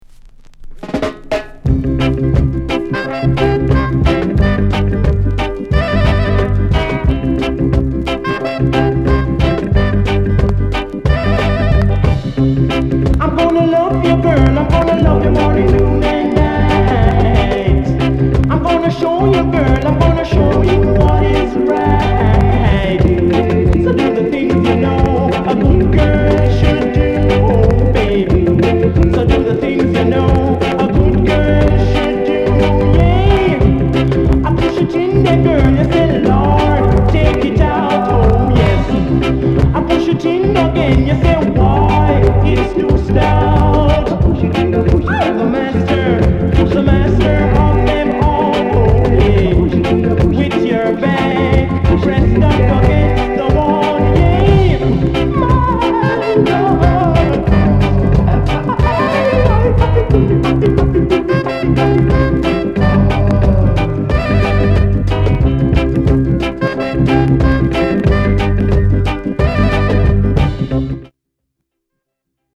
SKINHEAD INST